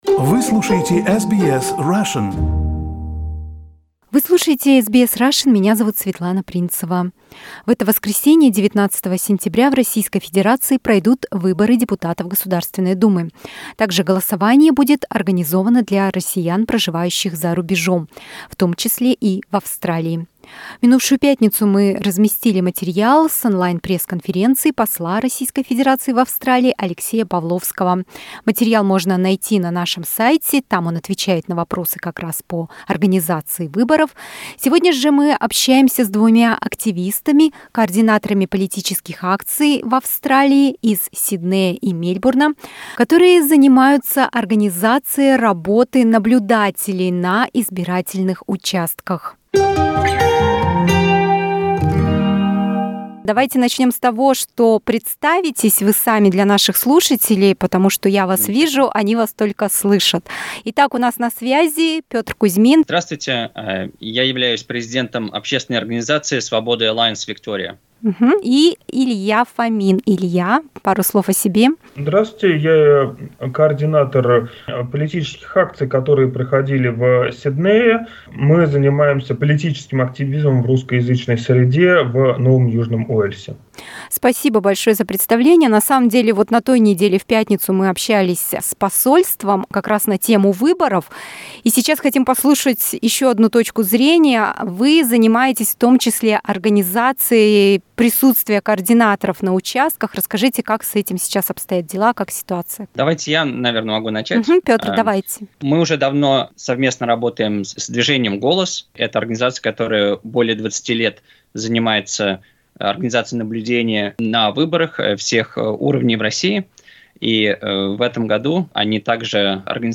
С момента записи интервью были получены важные обновления.